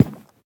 Minecraft Version Minecraft Version 1.21.5 Latest Release | Latest Snapshot 1.21.5 / assets / minecraft / sounds / block / cherry_wood_hanging_sign / step3.ogg Compare With Compare With Latest Release | Latest Snapshot
step3.ogg